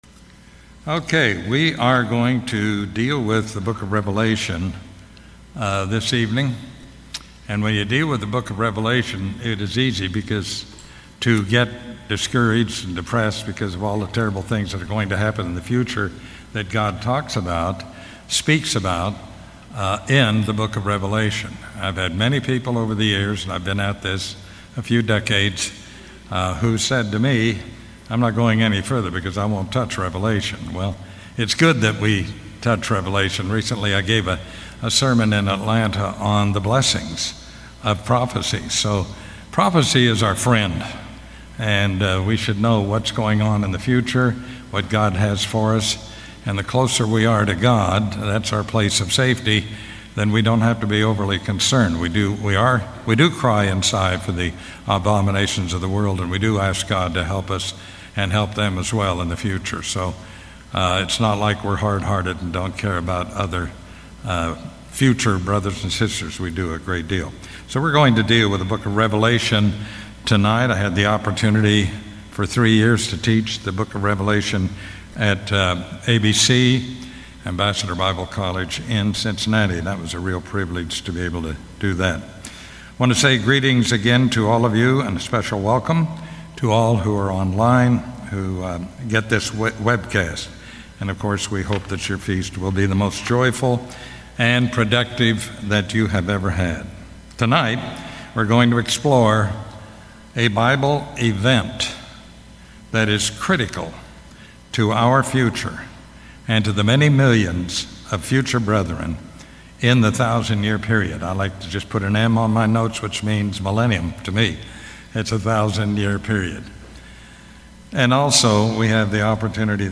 This message was given during the 2024 Feast of Tabernacles in Panama City Beach, Florida.